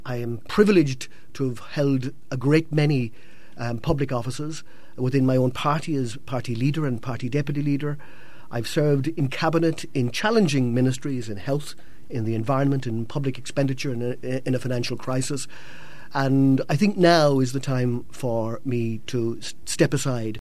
The 67-year-old says the time has come for him to bring an end to his political career: